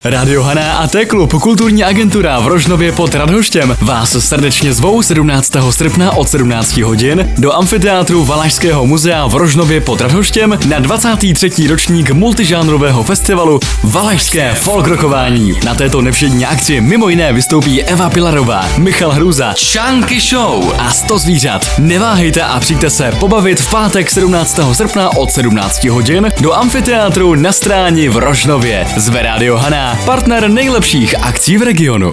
Spot Radio Haná |